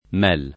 mel [ m $ l ]